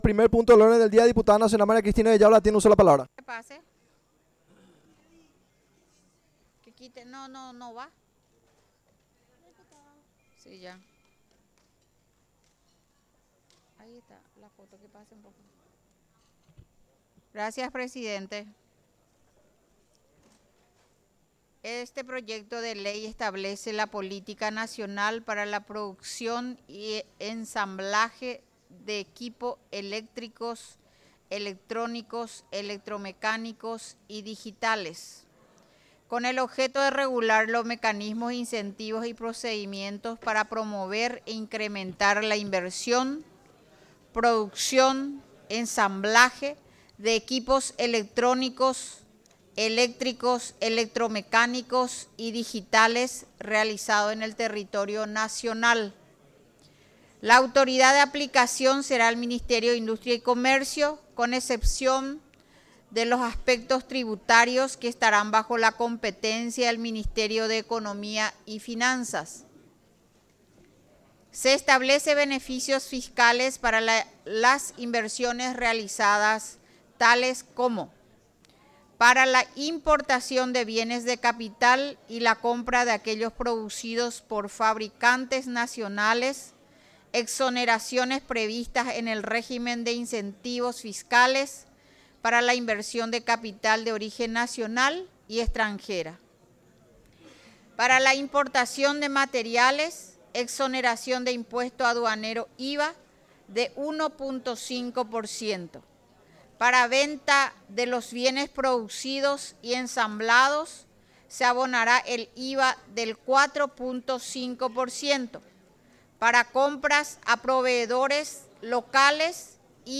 Honorable C�mara de Diputados - SESION DIGITAL